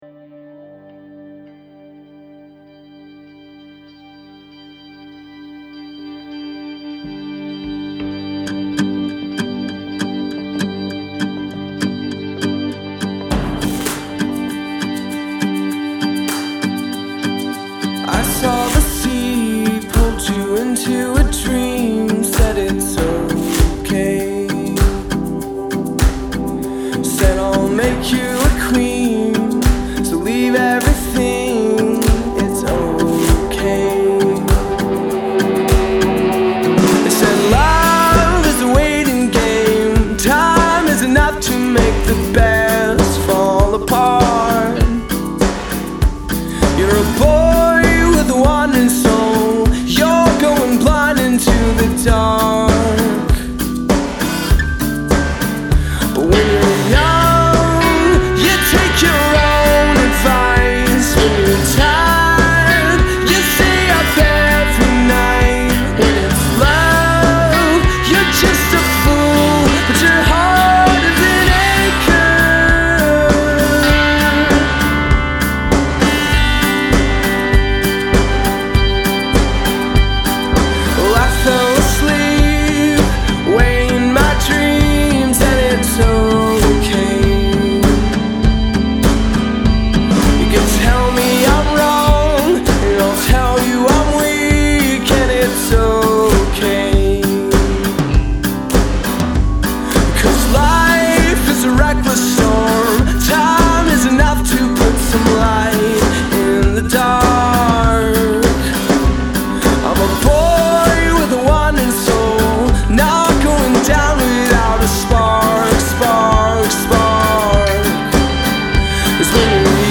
A little DCFC sounding.